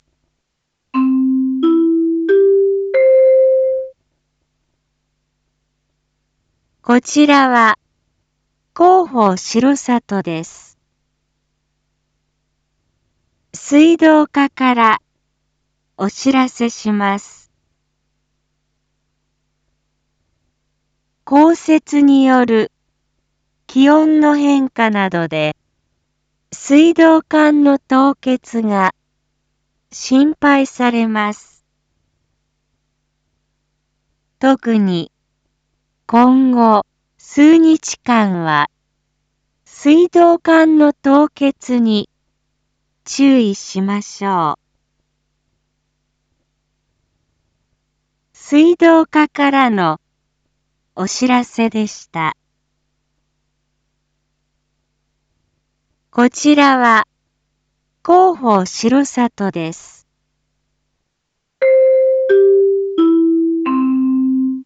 BO-SAI navi Back Home 一般放送情報 音声放送 再生 一般放送情報 登録日時：2024-02-05 19:01:07 タイトル：凍結にご注意ください インフォメーション：こちらは、広報しろさとです。